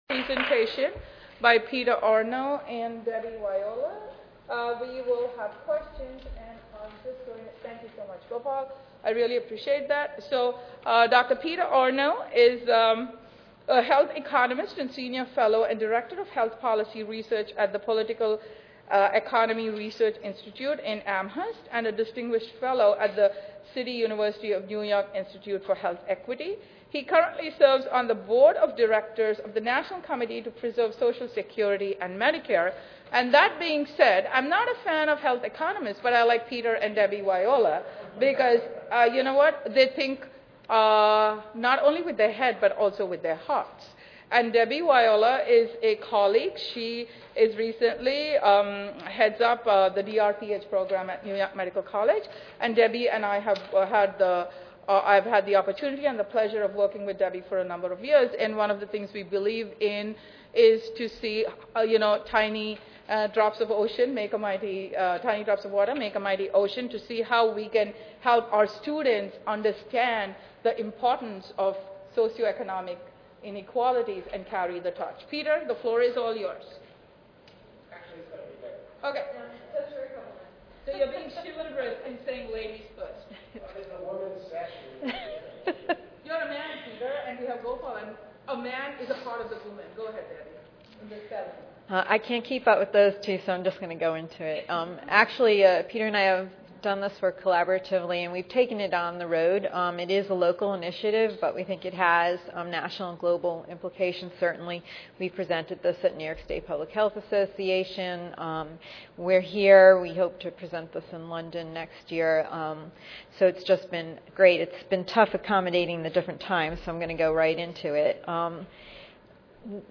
141st APHA Annual Meeting and Exposition (November 2 - November 6, 2013): Food insecurity, health problems and unmet needs of the elderly: A local perspective."
This presentation includes an overview of key findings and their impact on national and international long term care policy development for aging, food insecure elderly within our communities Learning Areas: Program planning Public health or related public policy Learning Objectives: Identify the major factors contributing to food insecurity among the elderly.